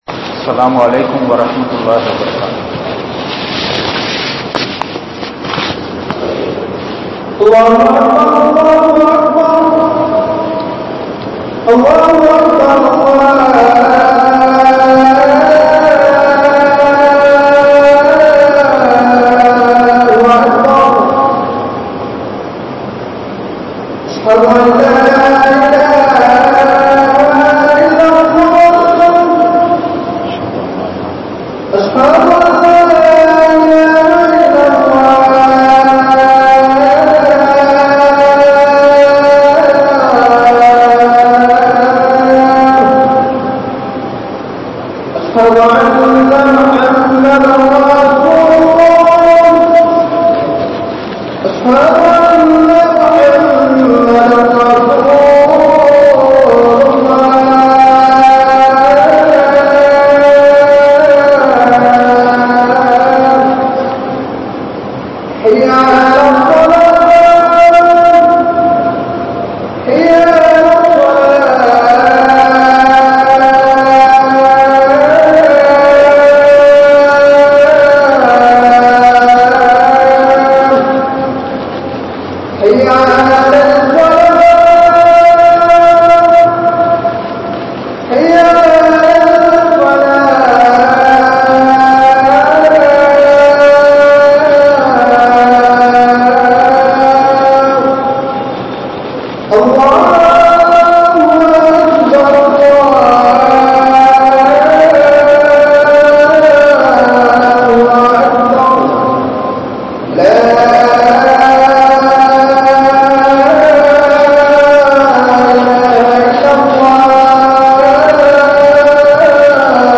Thooimaiyaana Samooham (தூய்மையான சமூகம்) | Audio Bayans | All Ceylon Muslim Youth Community | Addalaichenai